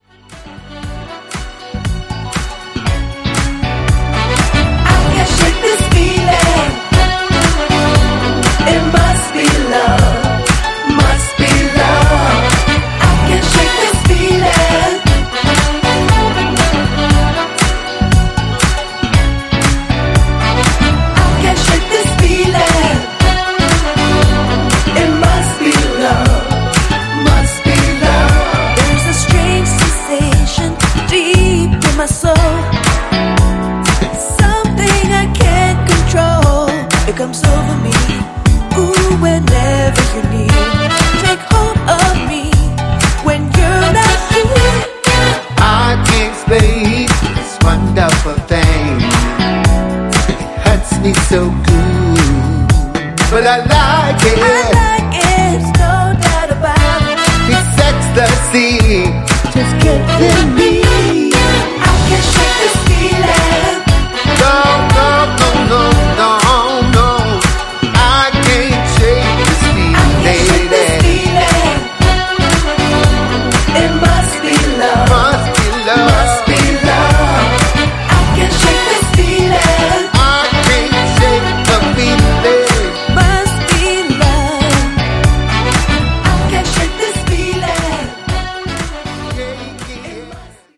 ジャンル(スタイル) DISCO / HOUSE